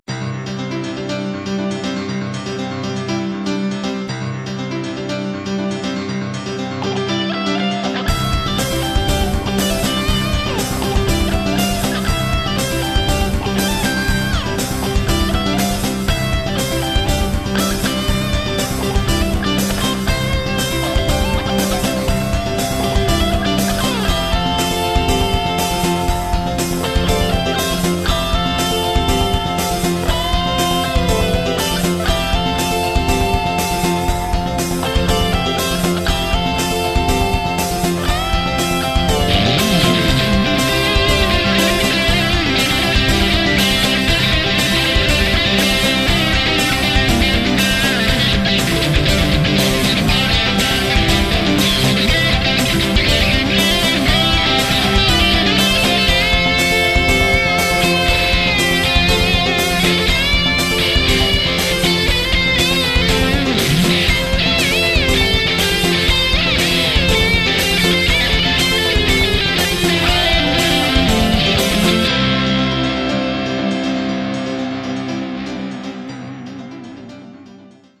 [Pop]